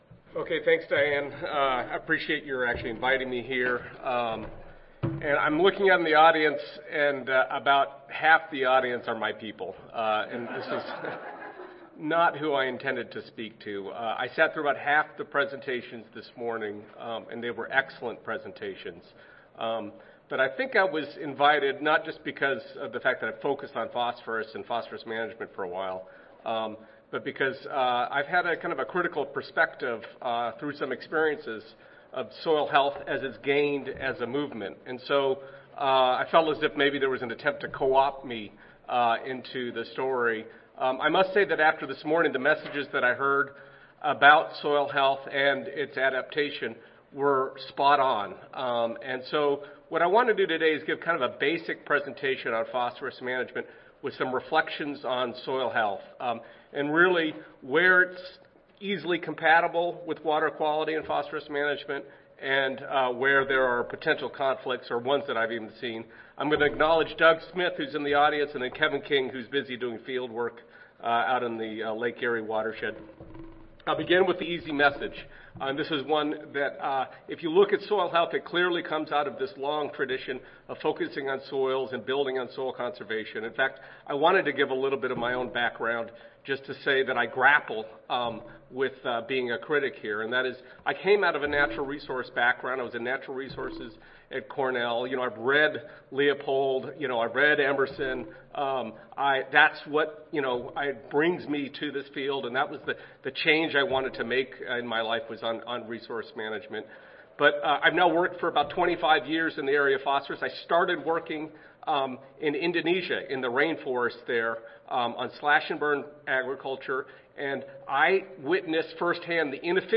See more from this Division: ASA Section: Environmental Quality See more from this Session: Symposium--Field Management for Improved Soil Health and Environmental Quality